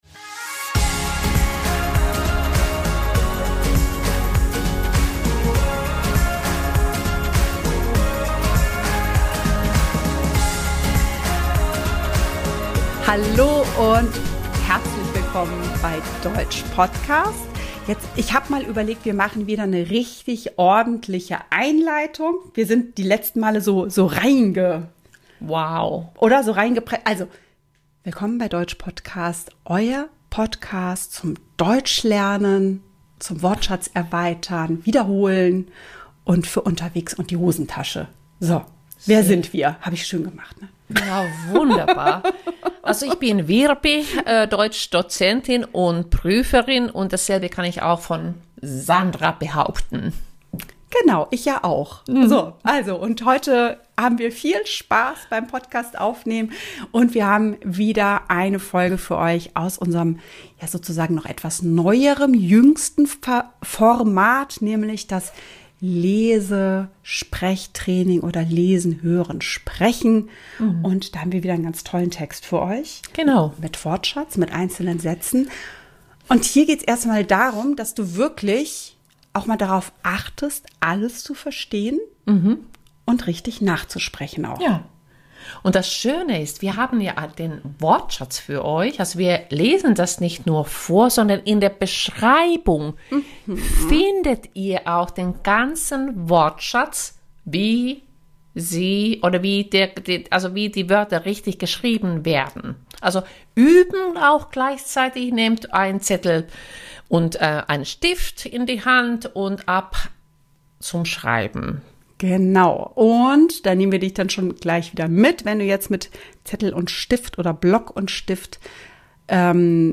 Dann lesen wir Text langsam und schnell.